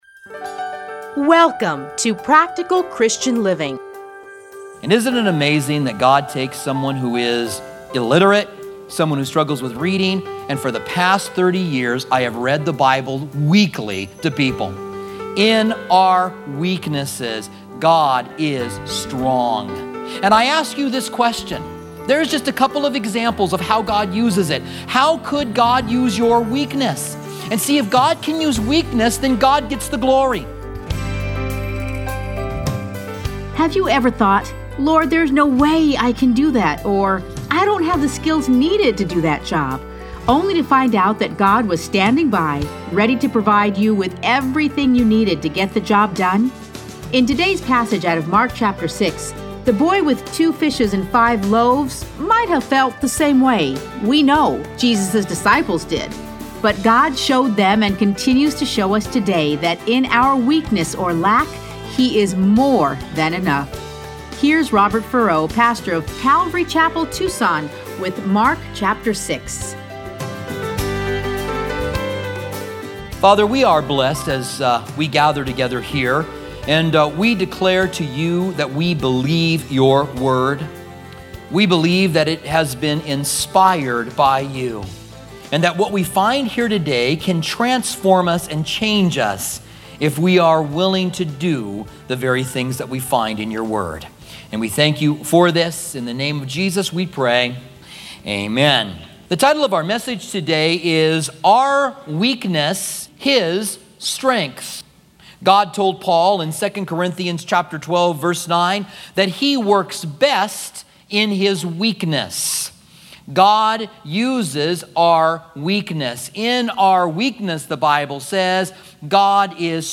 Listen to a teaching from Mark 6:30-43.